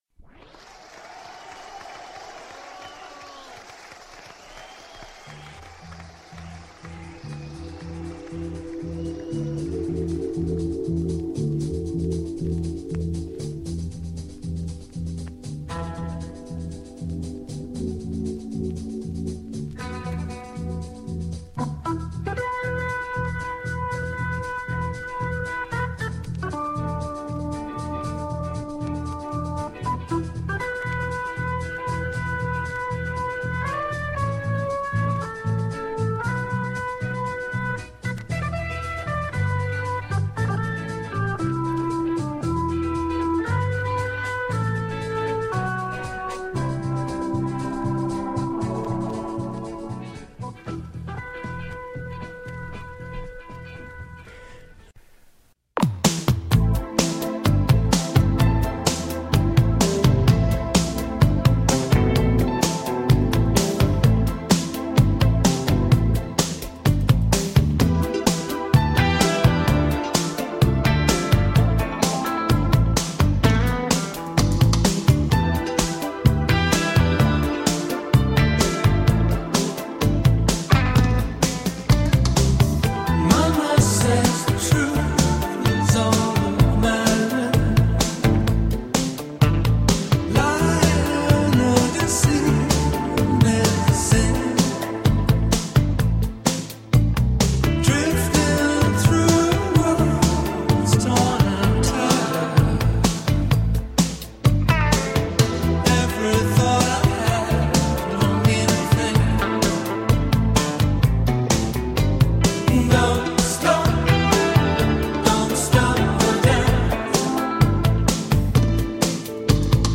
In lieu of the usual interview with an artist or writer, a talk "Our Money or Our Life"